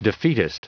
Prononciation du mot defeatist en anglais (fichier audio)